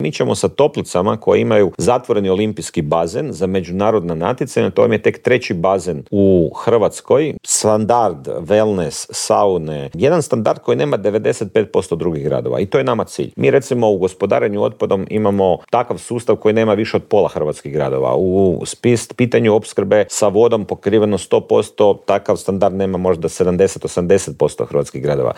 ZAGREB - "Bilo je i vrijeme da se gospodarske mjere relaksiraju, građani sada moraju preuzeti dio tereta na sebe. To neće ubrzati rast inflacije, a važno je da pomognemo onima koji su najugroženiji", u Intervjuu tjedna Media servisa poručio je saborski zastupnik iz redova HSLS-a Dario Hrebak.